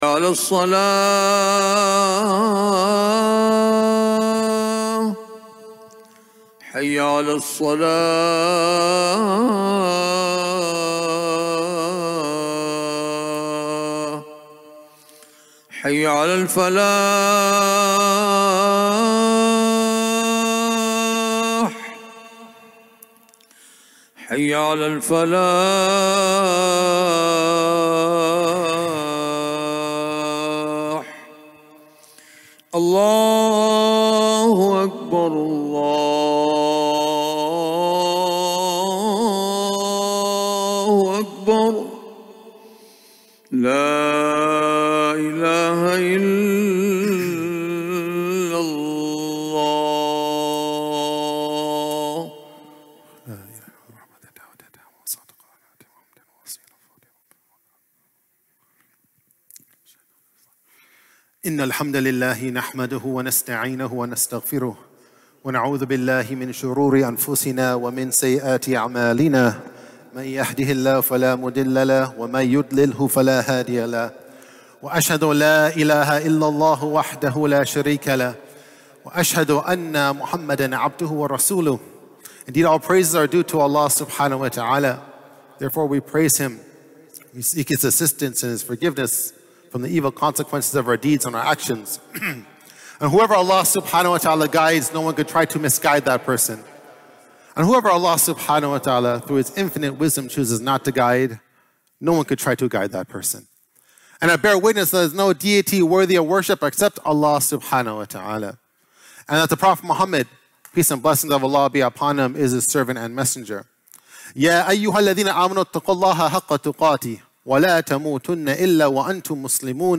Friday Khutbah - "Performance Review"